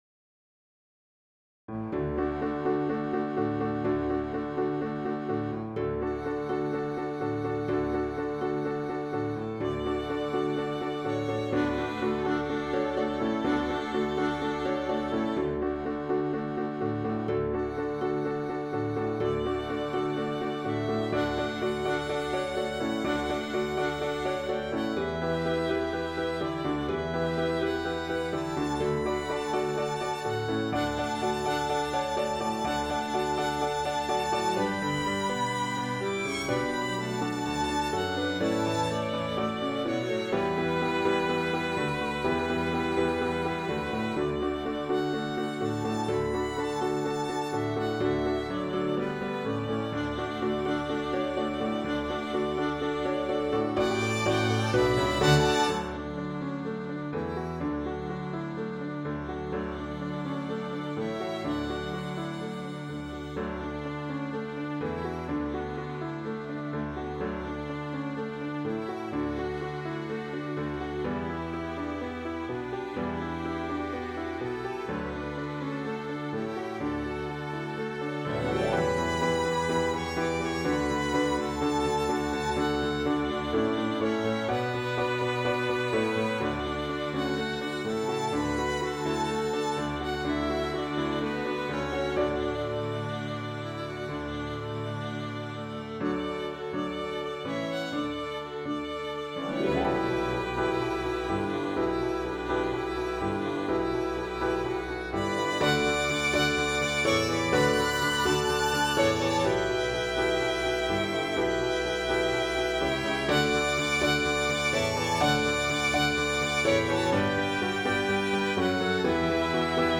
Christian, Gospel, Sacred, Praise & Worship.